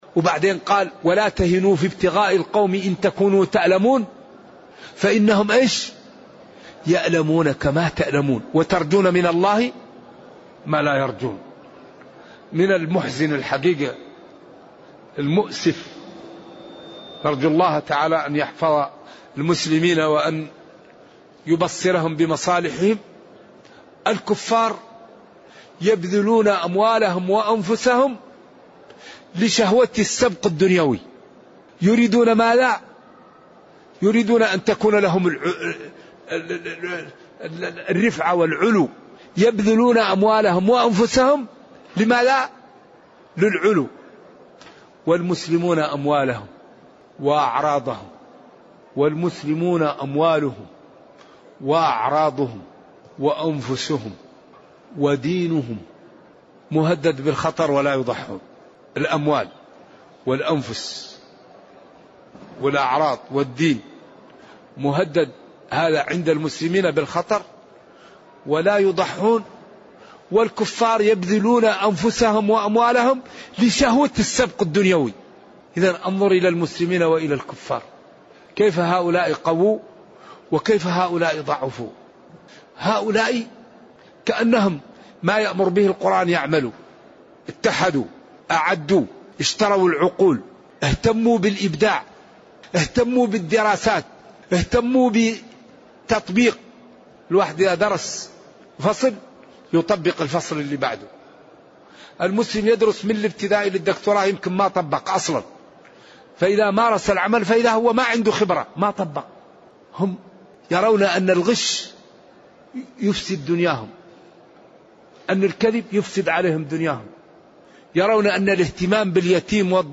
قطعة من المجلس الخامس عشر من تفسير سورة الأنفال. في تلك الفترة 1431هـ / 2010م توغل الصهاينة في غزة.